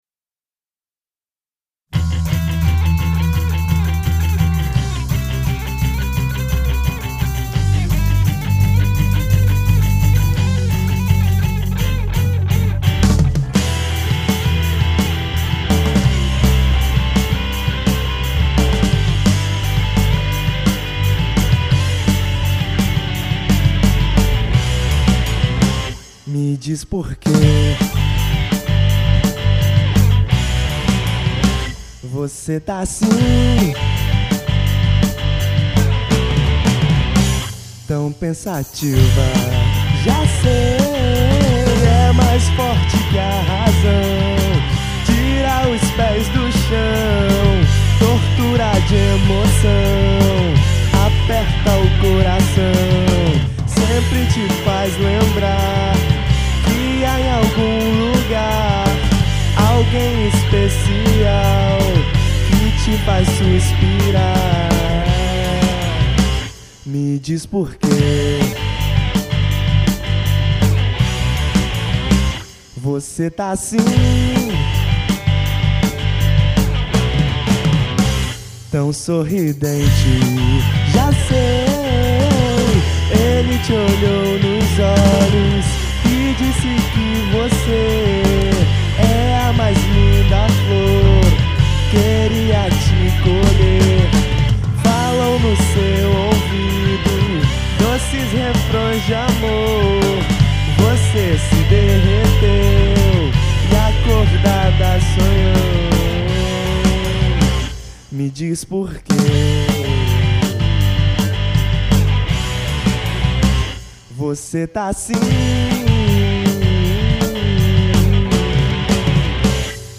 EstiloPop Rock